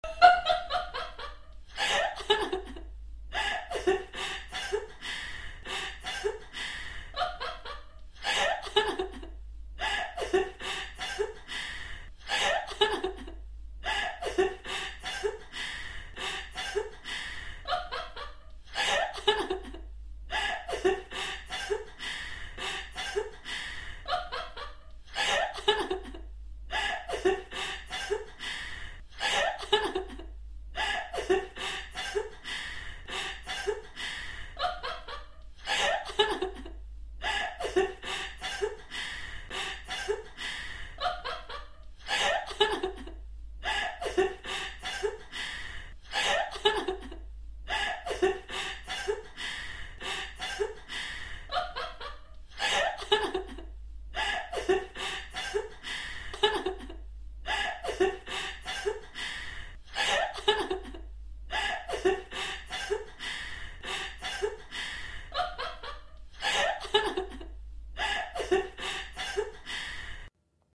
3D spatial surround sound "The girl's laughter"
3D Spatial Sounds